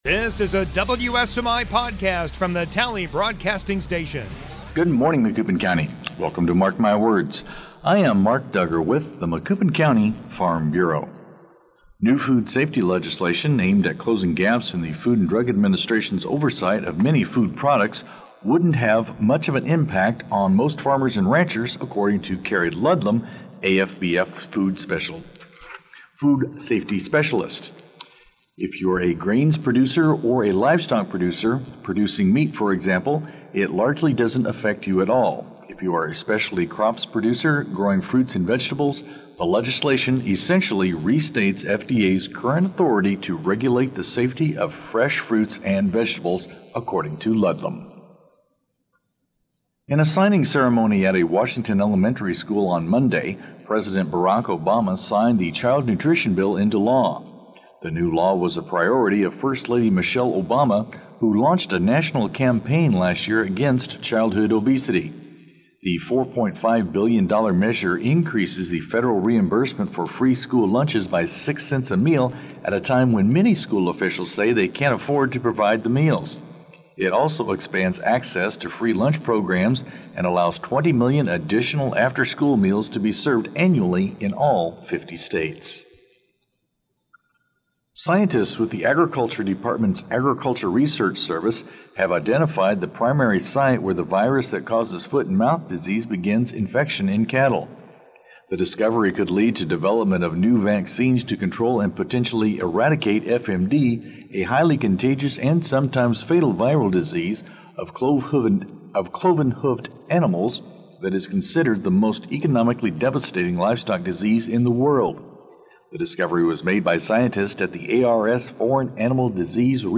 Podcasts - Interviews & Specials